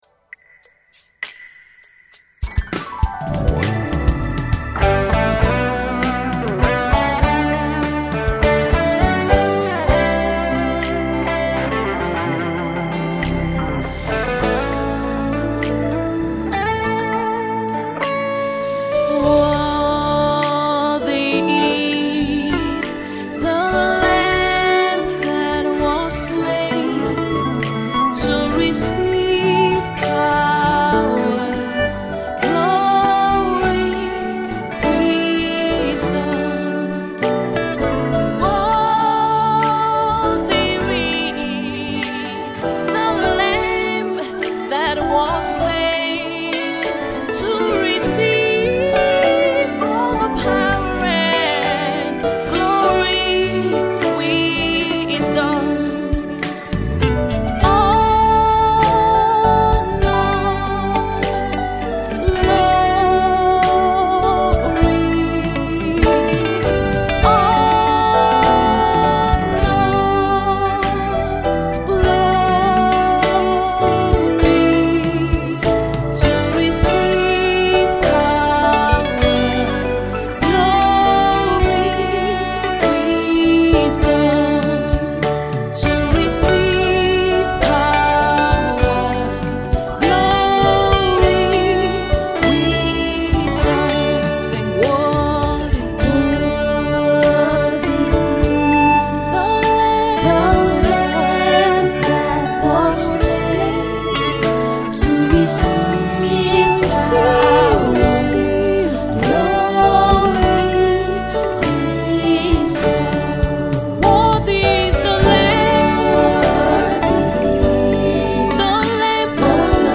Nigeria-based Gospel recording artiste